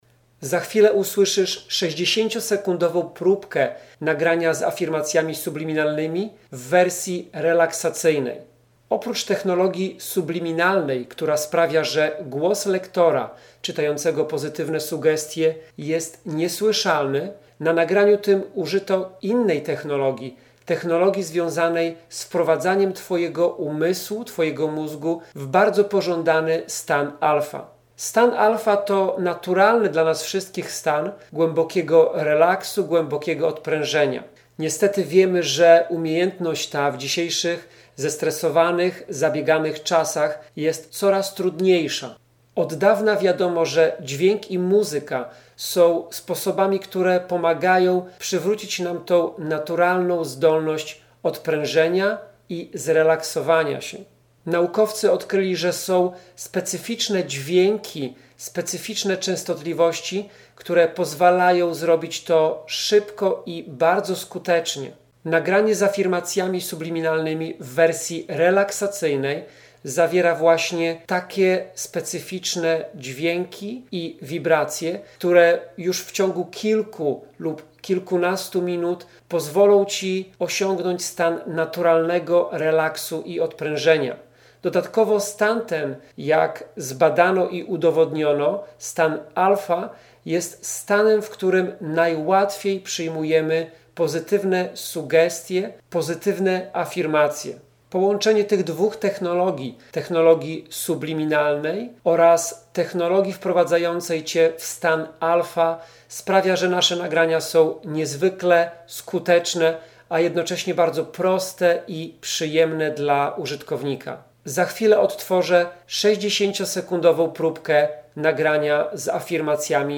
Nagranie RELAKSACYJNE
To specjalna ścieżka dźwiękowa Audio mp3, która już po kilkunastu minutach wprowadzi cię w stan ALFA.
Dzięki specjalnej technologii Subliminalnej™ głos lektora czytającego pozytywne afirmacje został ukryty na częstotliwości 14800Hz. Słuchając nagrania odnosisz więc wrażenie, że nie ma na nim zarejestrowanego żadnego głosu ludzkiego wypowiadającego pozytywne sugestie.
probka-relaksacja-alfa.mp3